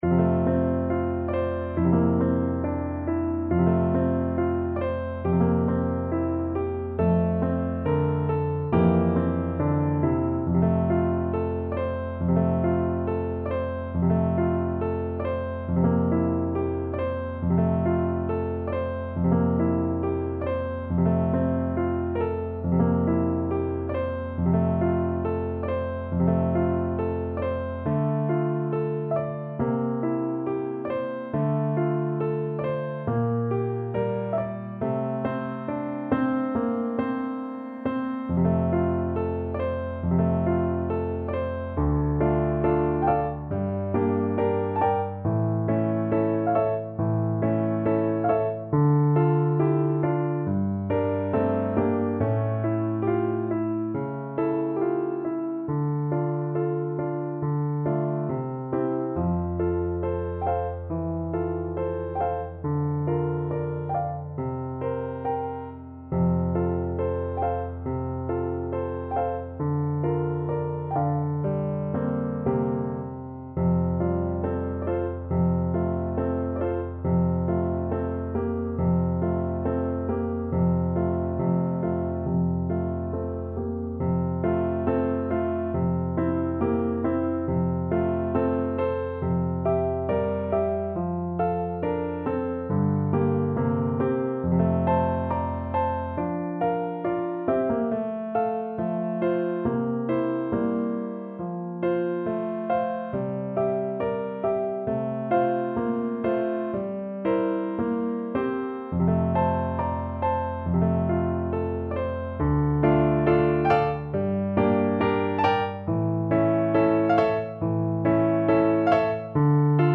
Traditional Music of unknown author.
~ = 69 Andante tranquillo
F major (Sounding Pitch) C major (French Horn in F) (View more F major Music for French Horn )